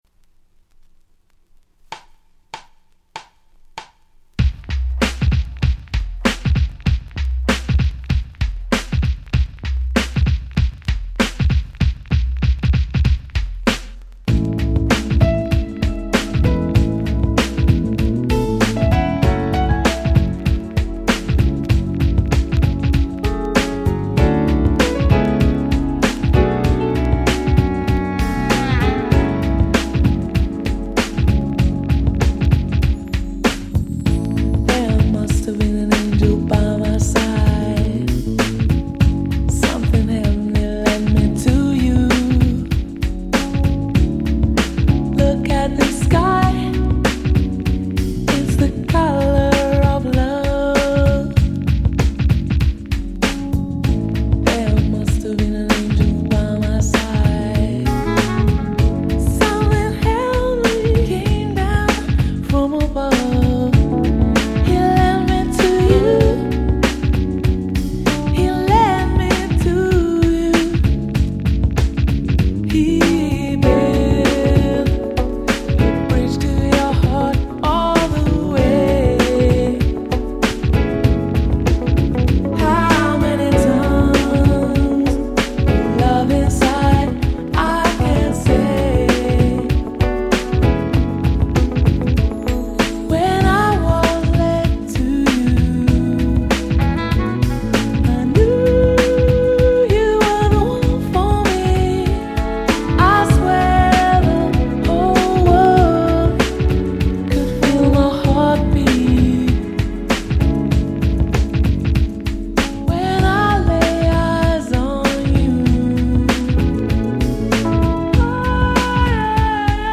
White Press Only Remix !!
92' Super Nice UK Soul !!